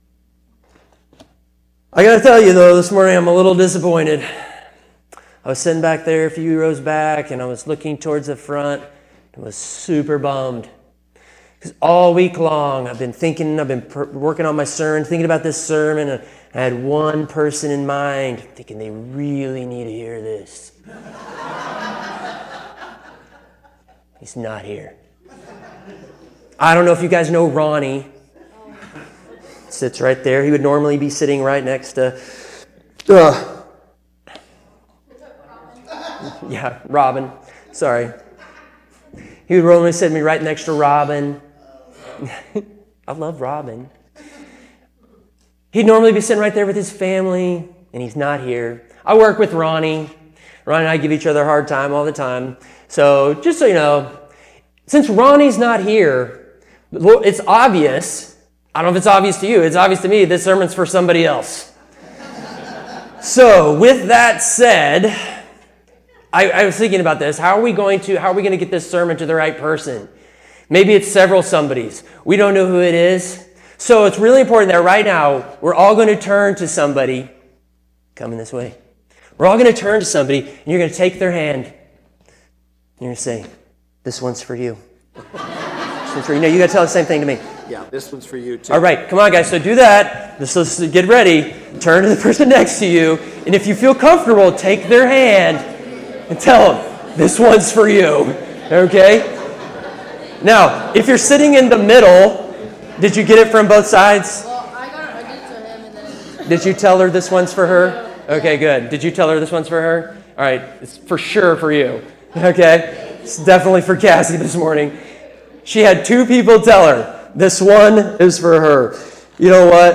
Sermons in this Series